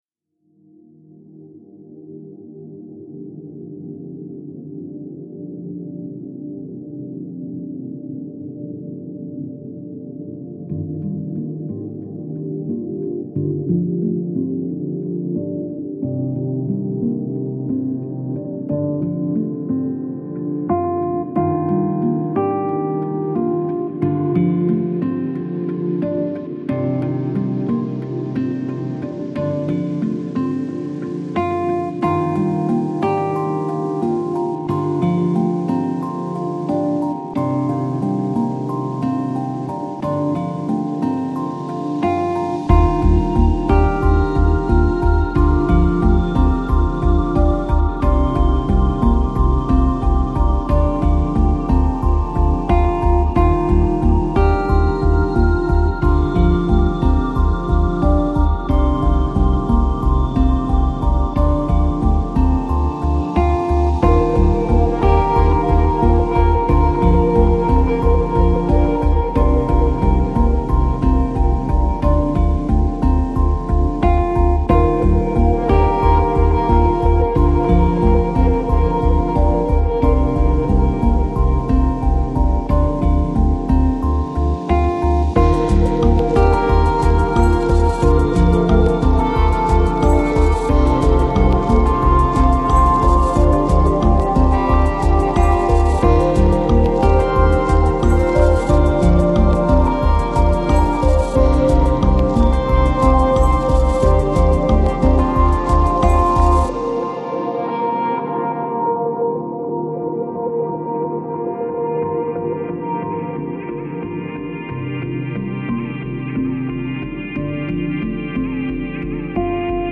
Жанр: Chillout